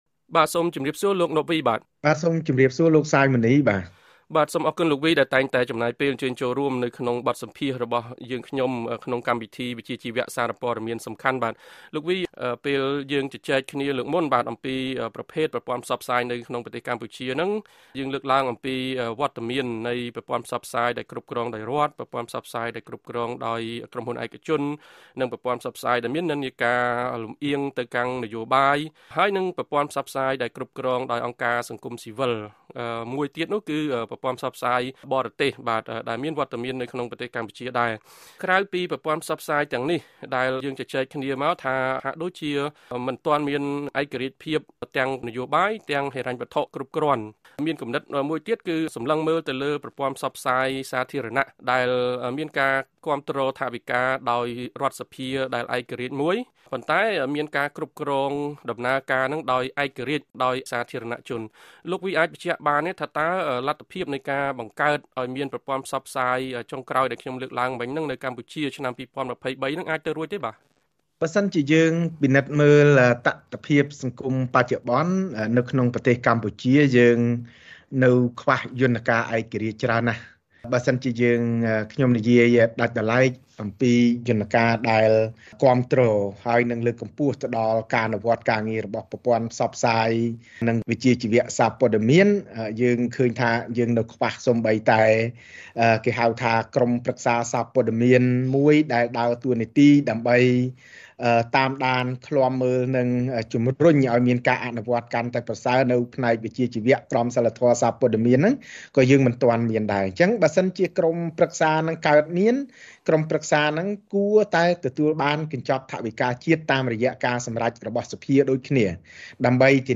បទសម្ភាសន៍៖ អ្នកជំនាញជំរុញគណបក្សនយោបាយឱ្យបញ្ចូលប្រព័ន្ធផ្សព្វផ្សាយសាធារណៈក្នុងគោលនយោបាយបក្ស